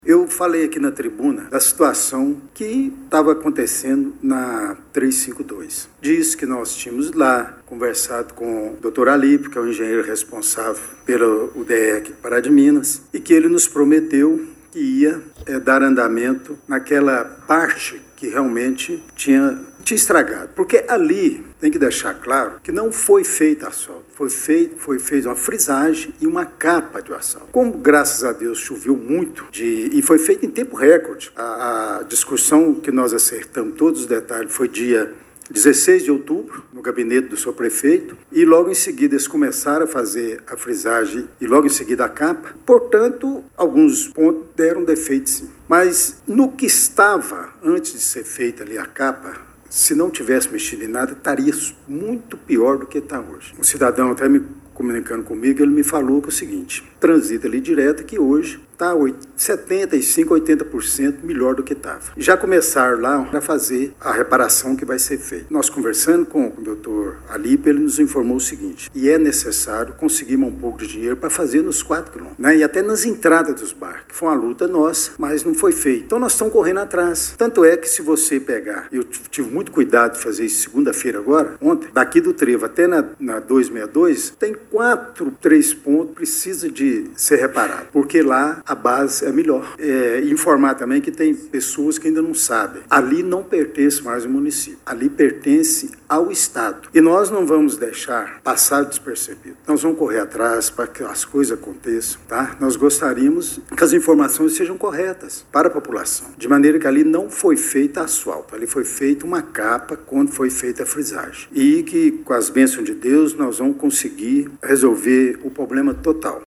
Durante reunião da Câmara Municipal de Pará de Minas, realizada ontem, 31 de março, o vereador Délio Alves Ferreira (PL) voltou a abordar os problemas no recapeamento da BR-352, executado no fim de 2025 com investimento público significativo.